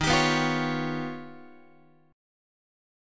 Eb+9 Chord
Listen to Eb+9 strummed